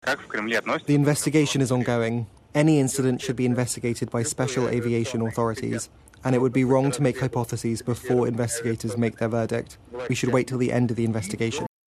Speaking through an interpreter, Kremlin spokesperson Dmitry Peskov says there needs to be an investigation.